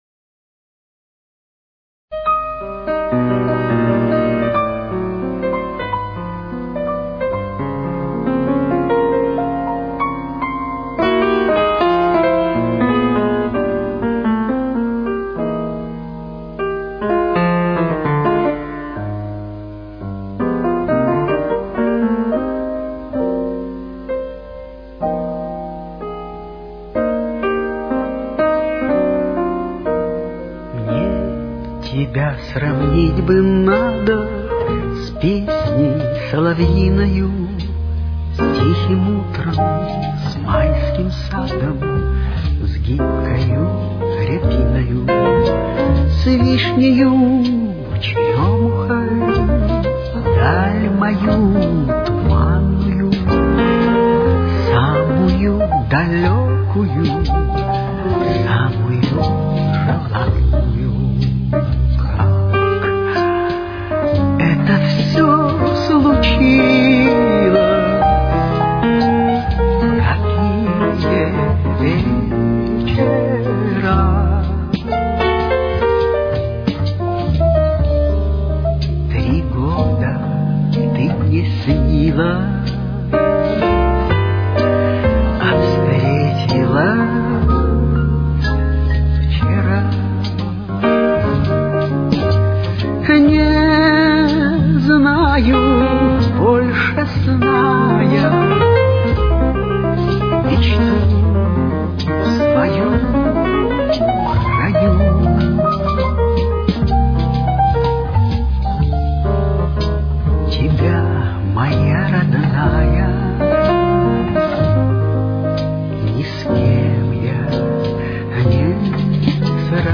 До минор. Темп: 66.